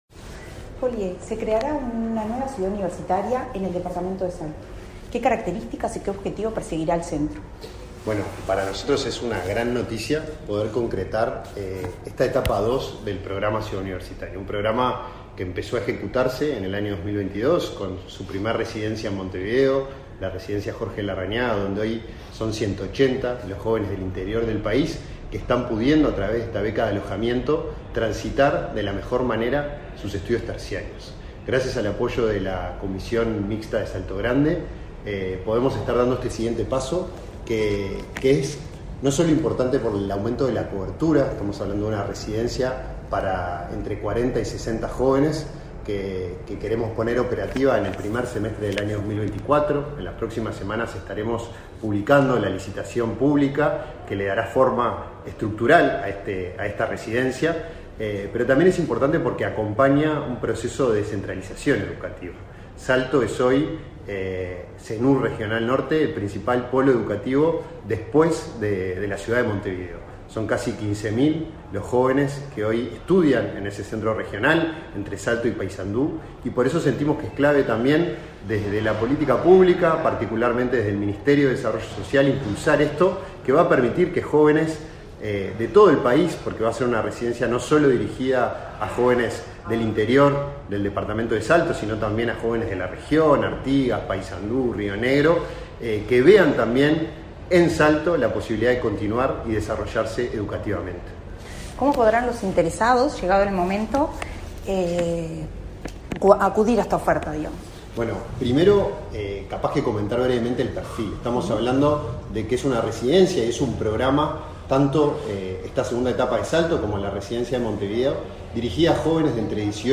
Entrevista al director del INJU, Felipe Paullier
Entrevista al director del INJU, Felipe Paullier 31/05/2023 Compartir Facebook X Copiar enlace WhatsApp LinkedIn El director del Instituto Nacional de la Juventud (INJU), Felipe Paullier, en entrevista con Comunicación Presidencial, explicó los avances de la nueva residencia estudiantil en Salto.